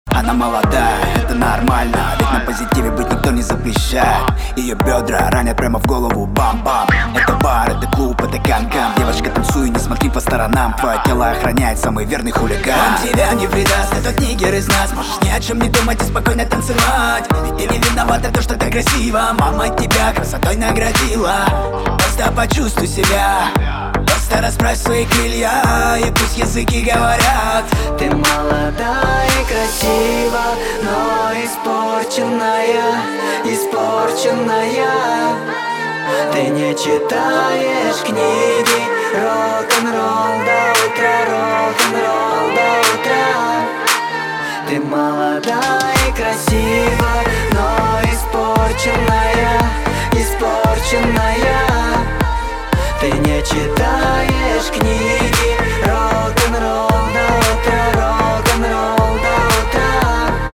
• Качество: 320, Stereo
цикличные